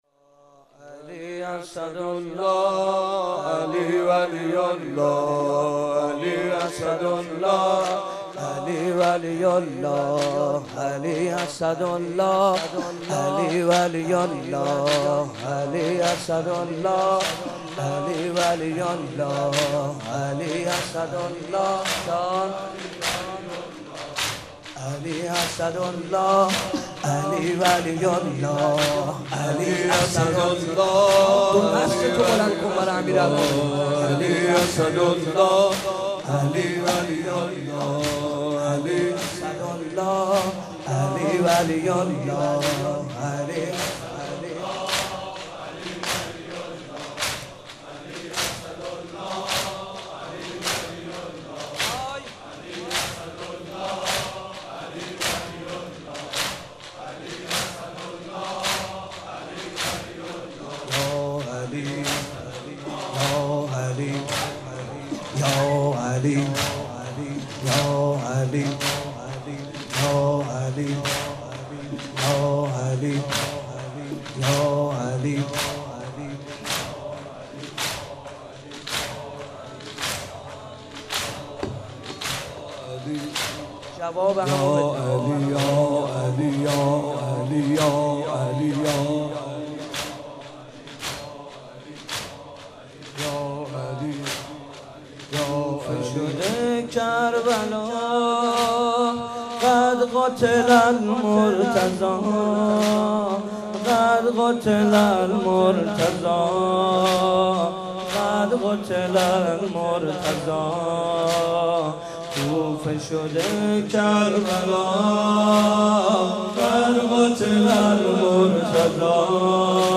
مناسبت : شب نوزدهم رمضان - شب قدر اول
قالب : زمینه